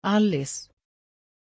Pronunția numelui Alice
pronunciation-alice-sv.mp3